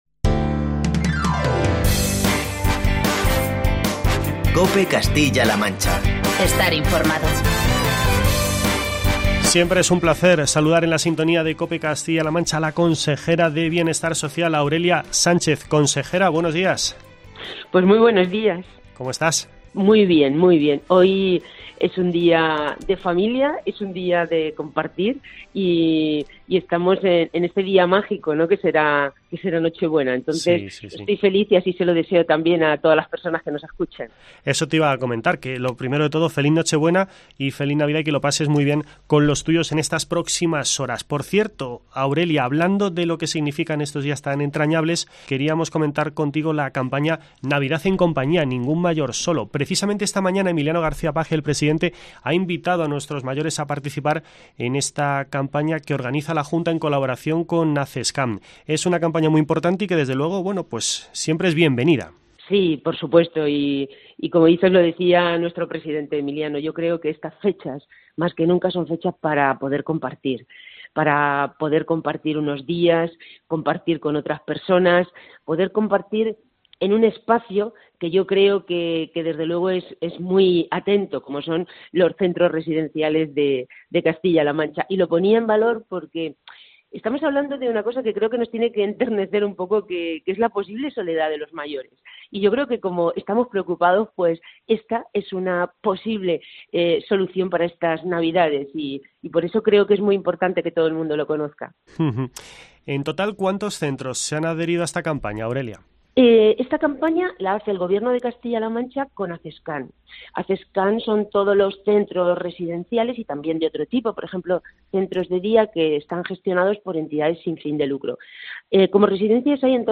Entrevista con Aurelia Sánchez, consejera de Bienestar Social